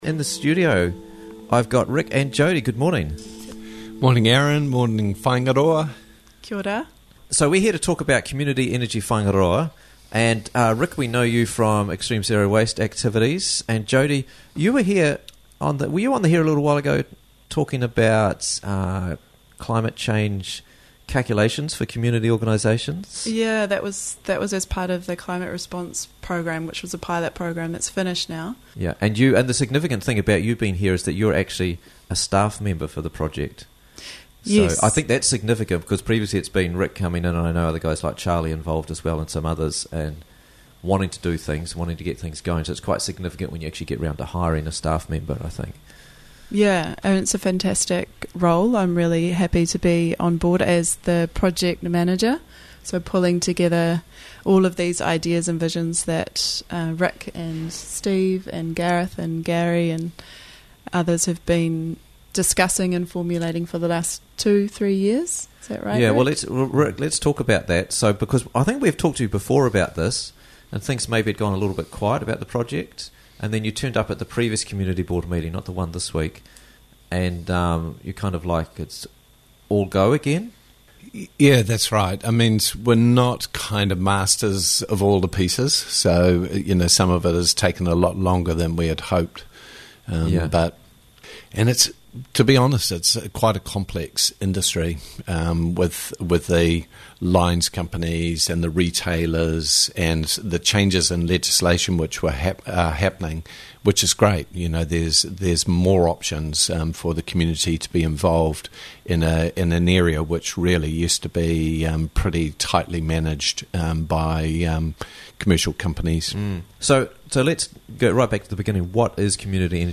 Community Energy Whaingaroa - Interviews from the Raglan Morning Show